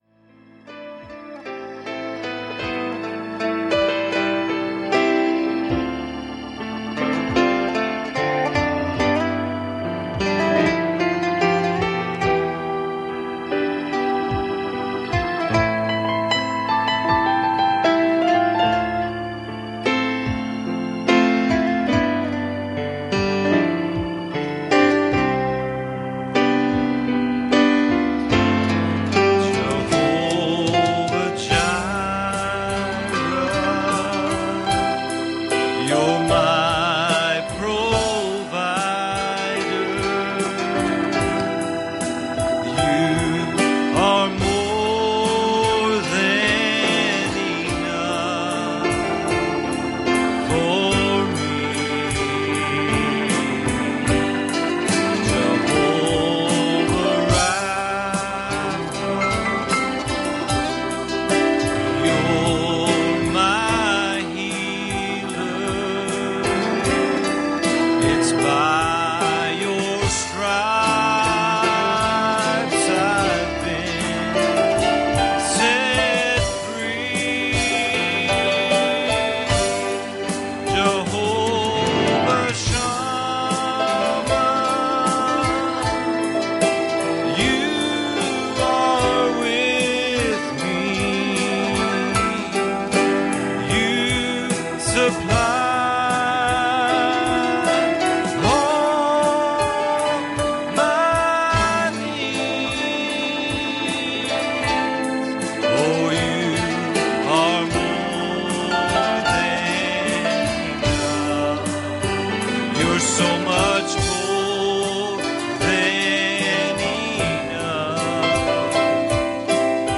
Passage: Luke 5:3 Service Type: Sunday Morning